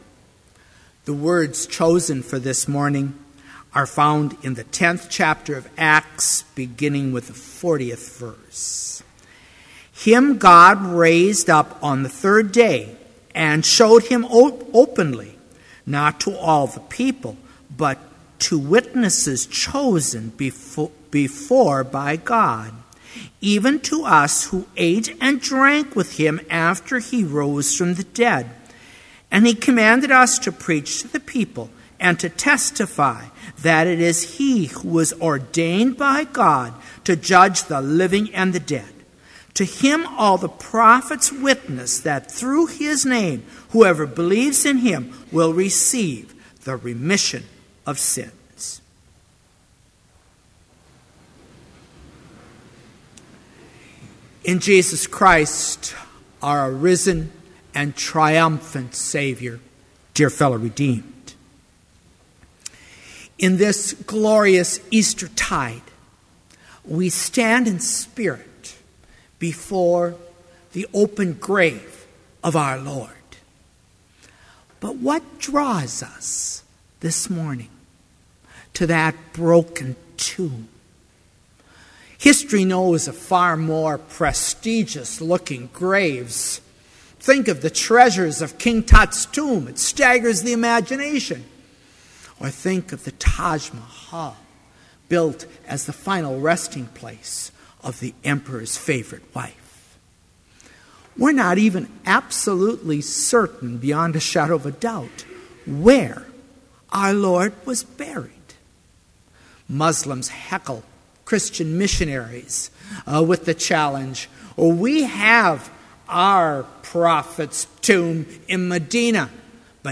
Complete service audio for Chapel - April 4, 2013